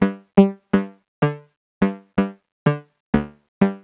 cch_synth_hurled_125_Dm.wav